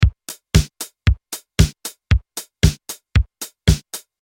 Chillwave-drum-loop-115-bpm.mp3